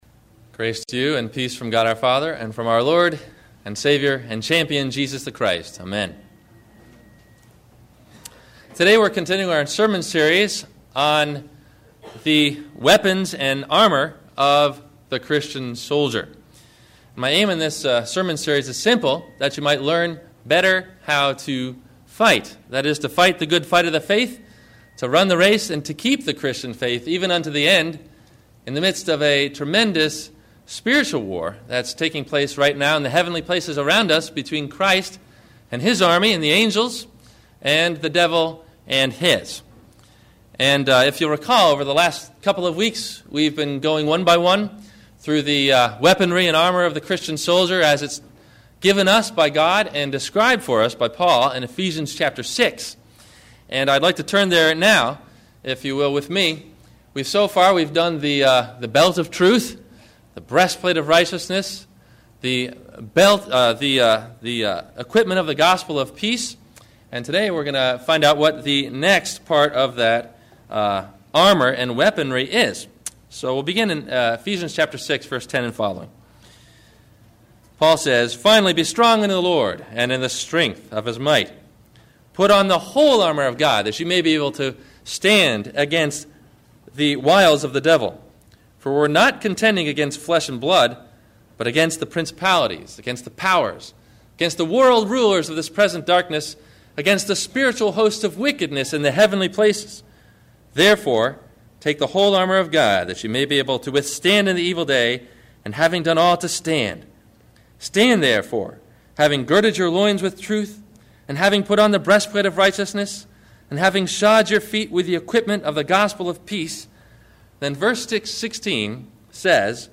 The Shield of Faith – Sermon – October 12 2008